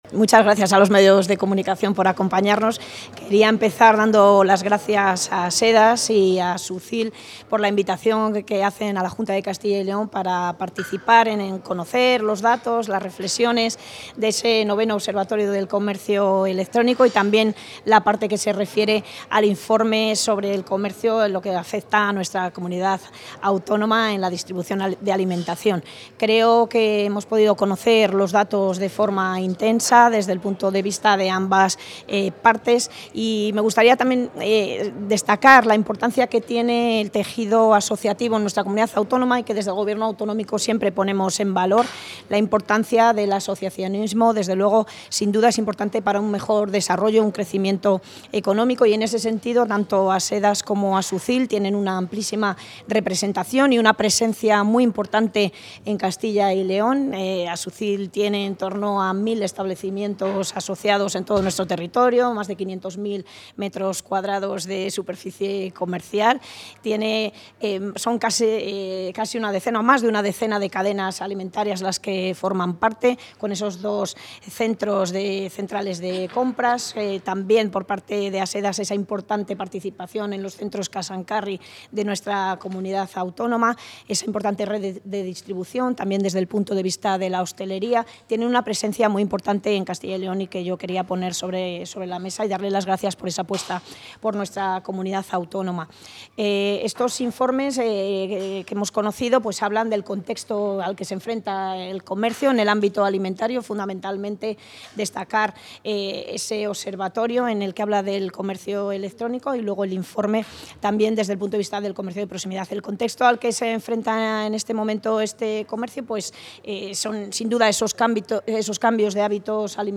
Intervención de la consejera.
La consejera de Industria, Comercio y Empleo, Leticia García, ha clausurado hoy en Valladolid la jornada organizada por la Asociación Española de Distribuidores, Autoservicios y Supermercados (ASEDAS) y la Asociación de empresarios de Supermercados de Castilla y León (ASUCyL), en la que se han presentado el IX Observatorio del Comercio Electrónico en Alimentación y el IV Informe de Distribución Alimentaria de Proximidad en Castilla y León.